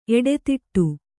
♪ eḍetiṭṭu